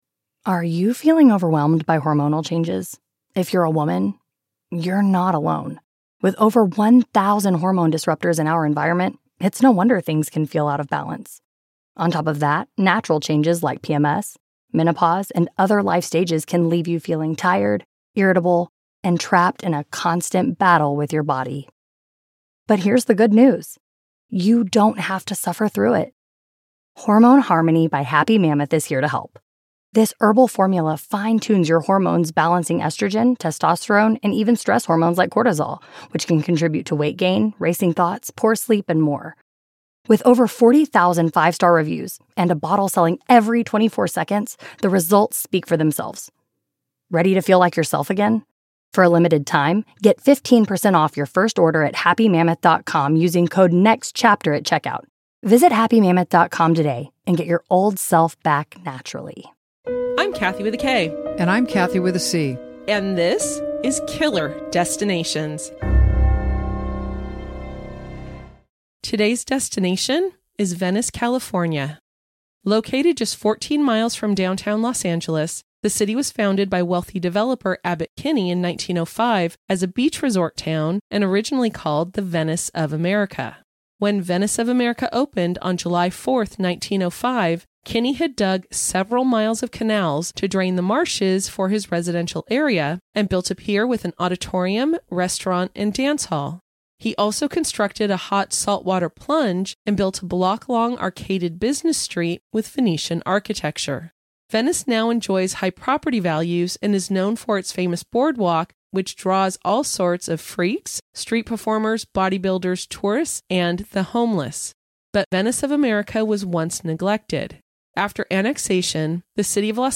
AND WE HAVE ONE OF THE JURORS AS A GUEST ON OUR PODCAST!